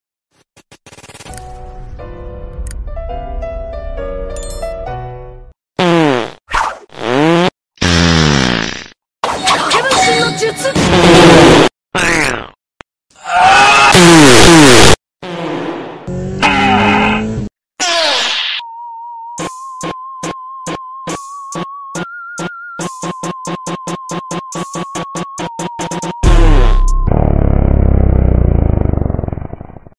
11 "Baby Farting" Sound Variations